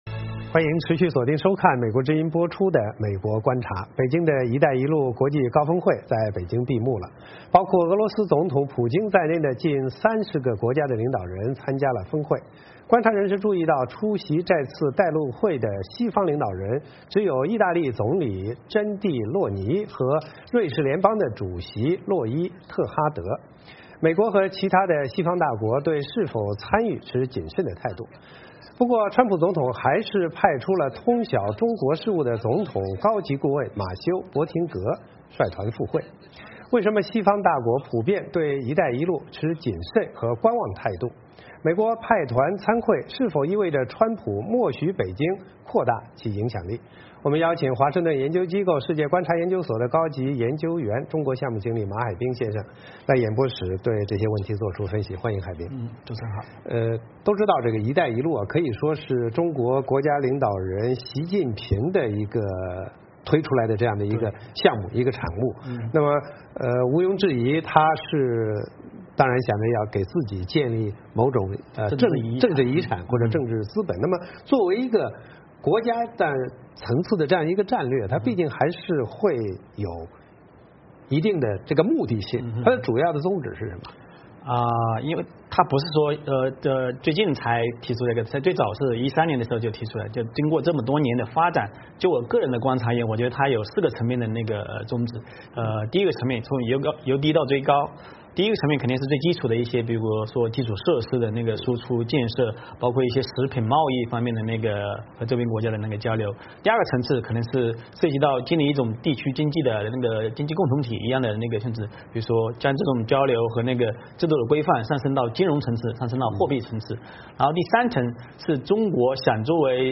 来演播室对此作出分析......